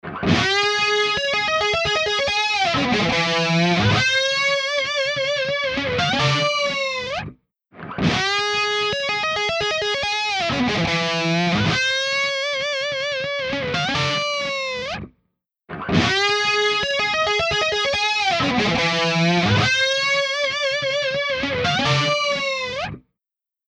H949 Harmonizer | Electric Guitar | Preset: 13 Cents
リバース、フランジ、ランダム機能を備えたデグリッチ・マルチエフェクト・プロセッサー